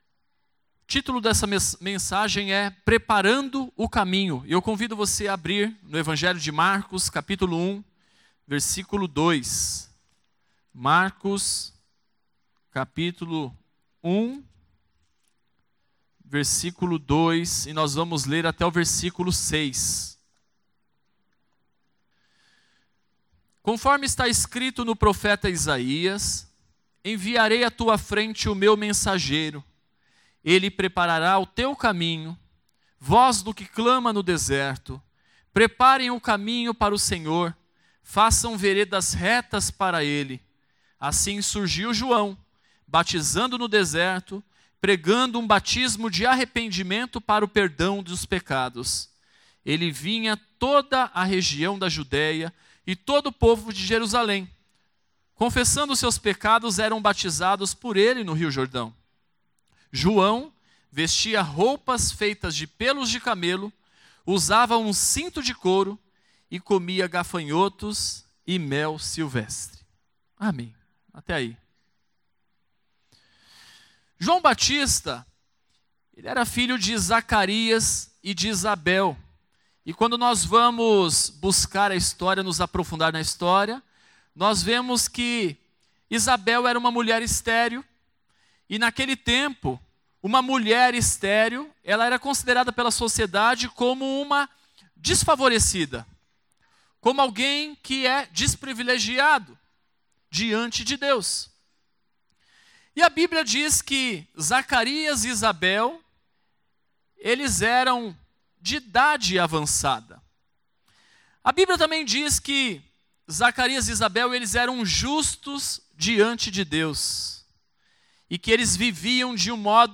Mensagem ministrada